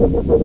tauChargeLoop.ogg